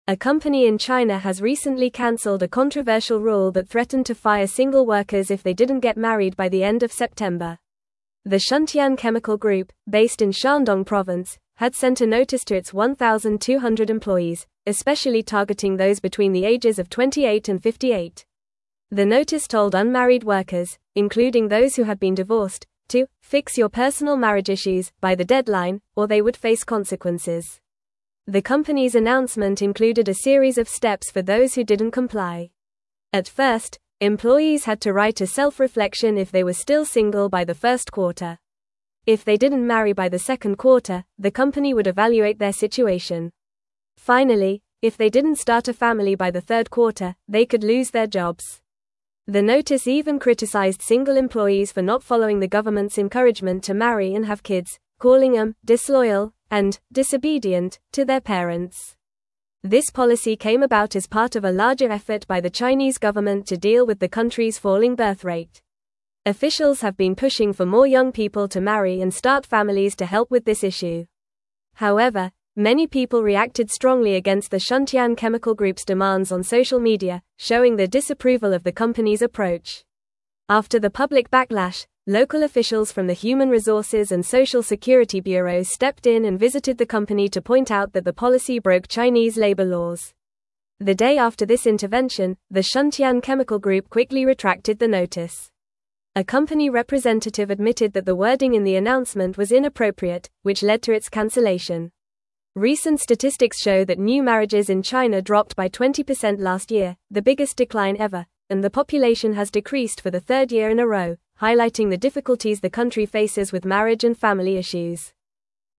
Fast
English-Newsroom-Upper-Intermediate-FAST-Reading-Company-Faces-Backlash-Over-Controversial-Marriage-Policy.mp3